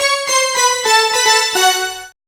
Synth Lick 49-05.wav